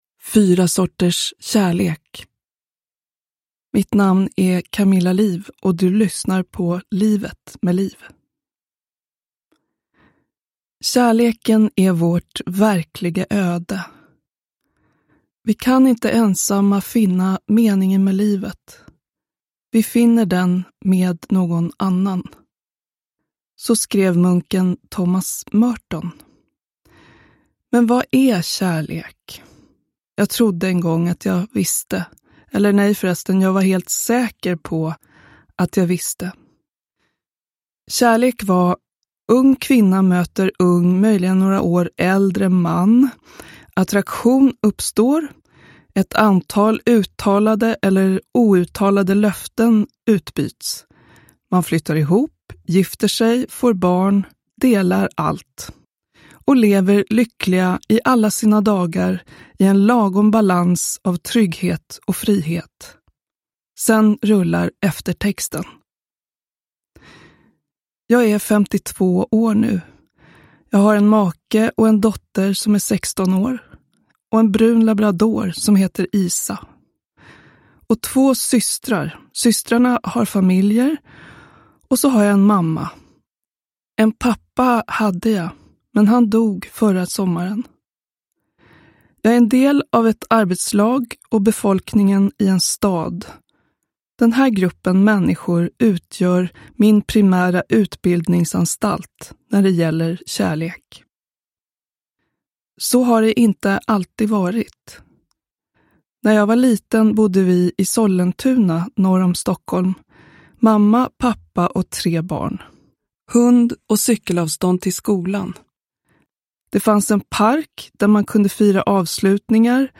Livet med Lif - 2 - Fyra sorters kärlek – Ljudbok – Laddas ner